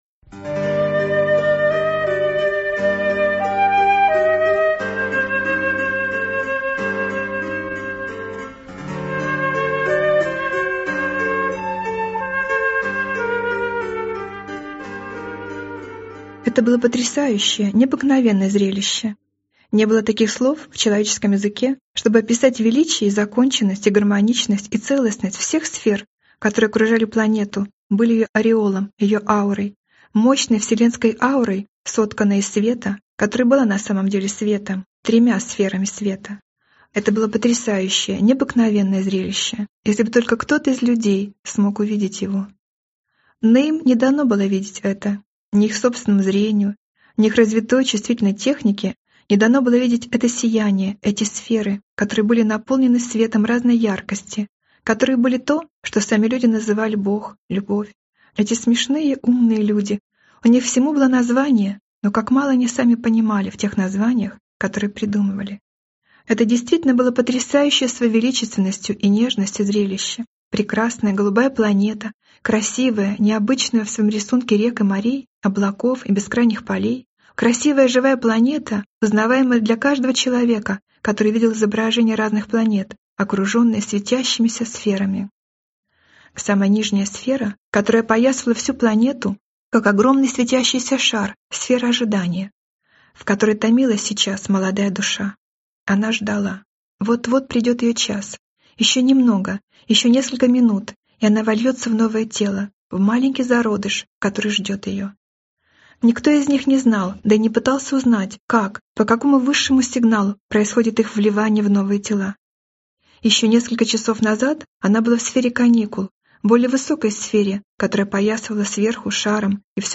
Аудиокнига Награда для души | Библиотека аудиокниг